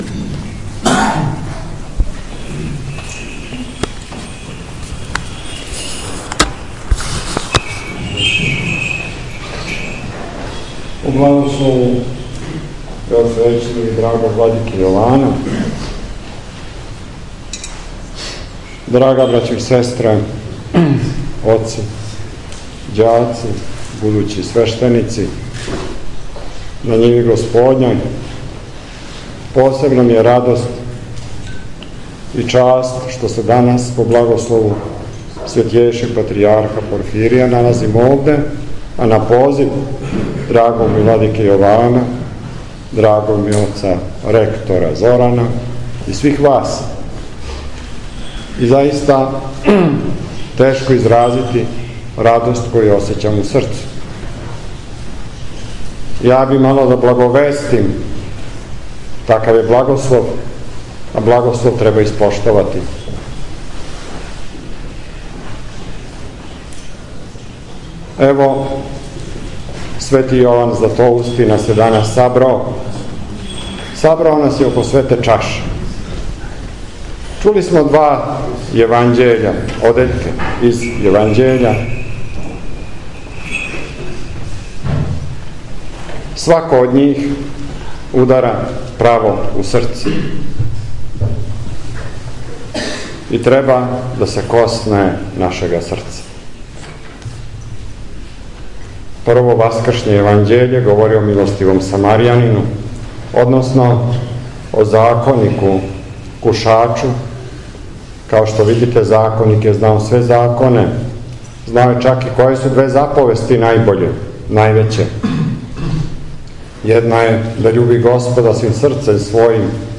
Након прочитаног Јеванђеља окупљенима се обратио Његово Преосвештенство Епископ хвостански Господин Алексеј рекавши:
Беседа Његовог Преосвештенства Епископа хвостанског г. Алексеја